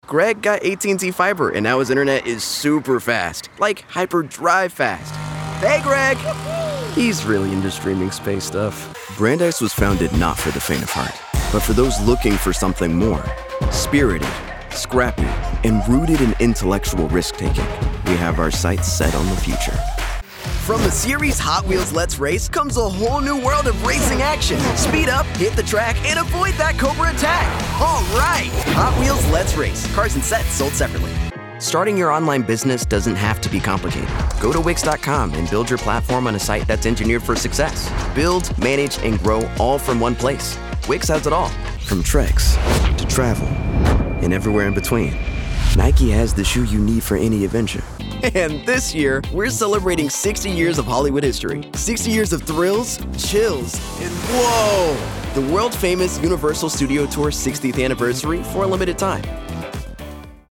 English (American)
Young, Urban, Cool, Versatile, Friendly
Commercial